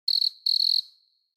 دانلود آهنگ شب 3 از افکت صوتی طبیعت و محیط
جلوه های صوتی